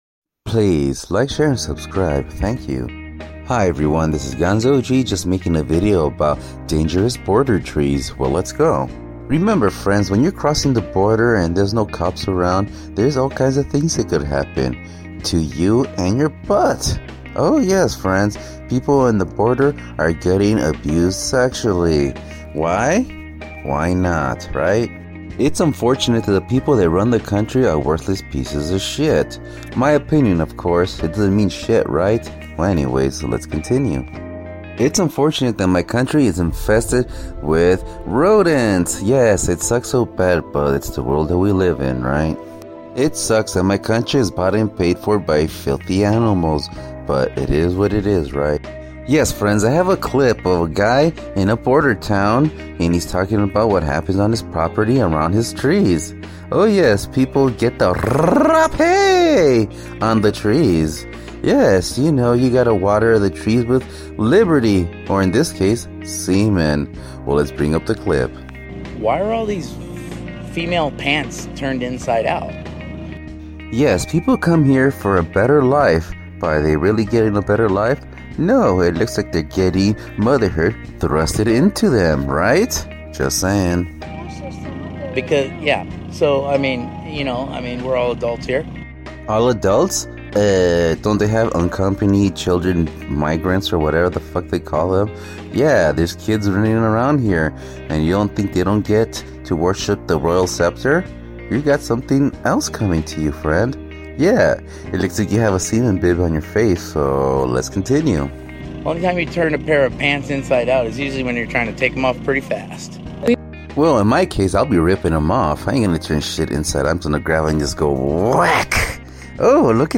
Rapping under the trees.